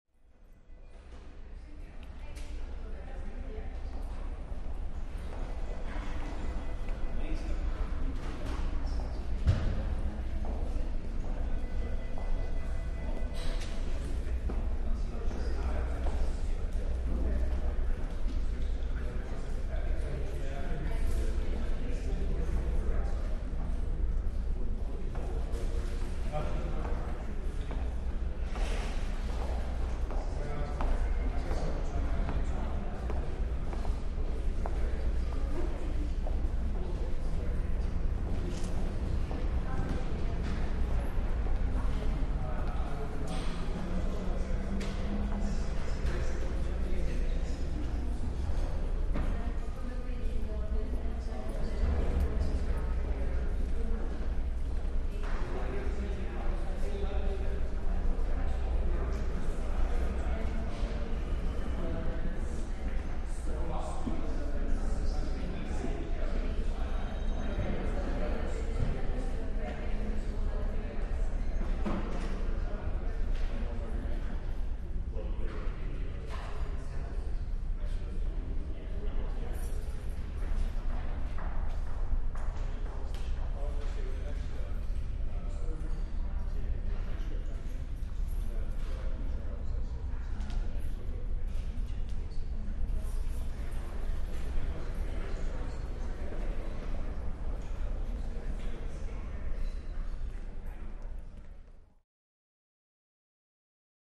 Lobby, Hotel | Sneak On The Lot
Hotel Lobby, Reverberant With Activity, Light Walla And Occasional Phone Rings.